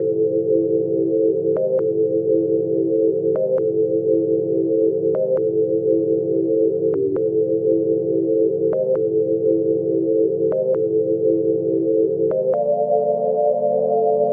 Pad_134_A.wav